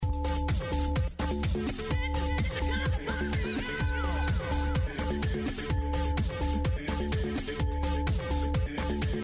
Thumbs up Awesome proggy tune - please ID